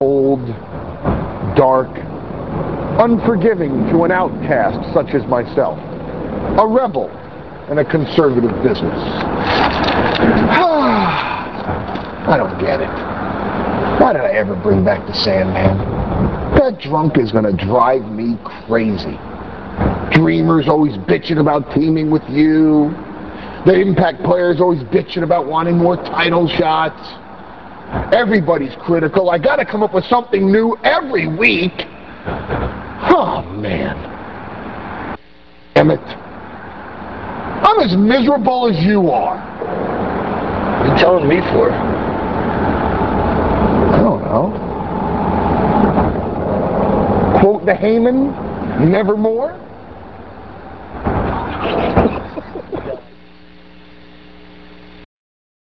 - This clip comes from ECW Hardcore TV - [11.06.99]. A promo about how unforgiving the world is to an outcast and a rebel ... by Paul Heyman! Heyman tells Raven he's as miserable as Raven is, "Quote the Heyman, nevermore" as Raven busts out laughing.